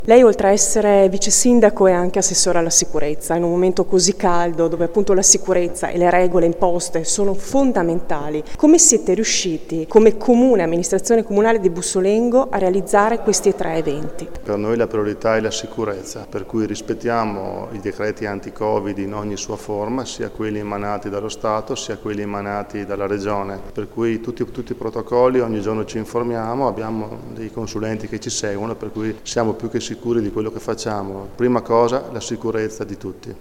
Il vicesindaco e assessore all’Agricoltura Giovanni Amantia:
Interviste